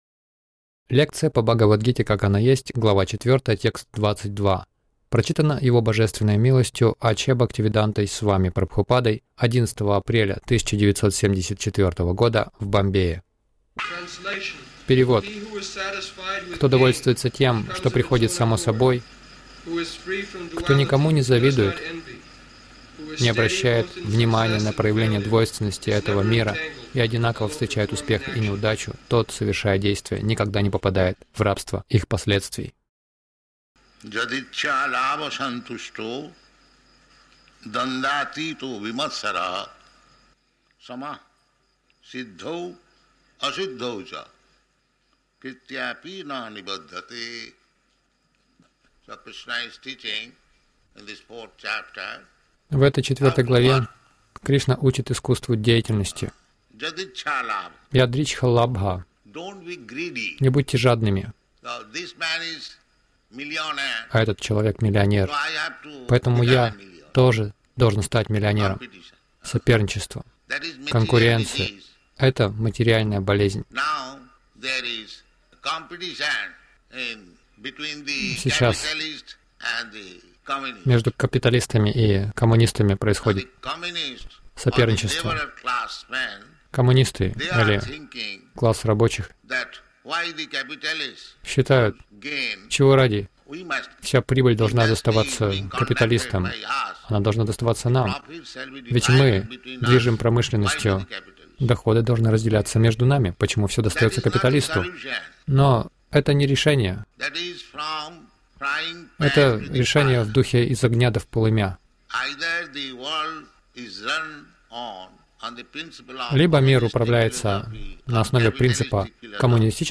Милость Прабхупады Аудиолекции и книги 11.04.1974 Бхагавад Гита | Бомбей БГ 04.22 — Не оставайтесь во тьме майи Загрузка...